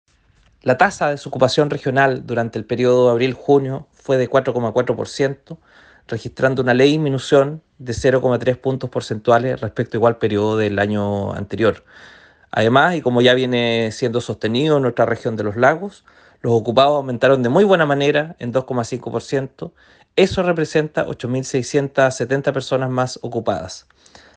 El Seremi del Trabajo y Previsión Social, Ángel Cabrera, manifestó que » los ocupados aumentaron de muy buena manera en 2,5%, lo que representa 8.671 personas más ocupadas. «
29-julio-23-Angel-Cabrera-Desocupacion.mp3